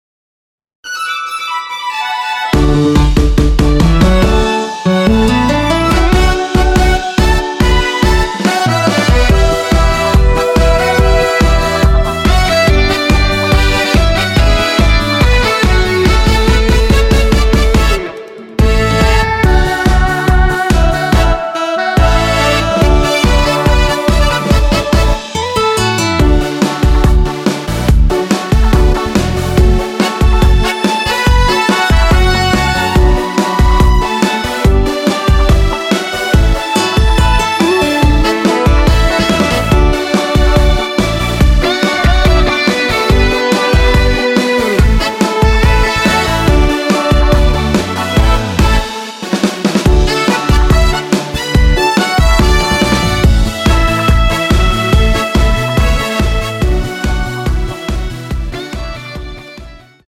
원키에서(-1)내린 멜로디 포함된 MR입니다.
D
노래방에서 노래를 부르실때 노래 부분에 가이드 멜로디가 따라 나와서
앞부분30초, 뒷부분30초씩 편집해서 올려 드리고 있습니다.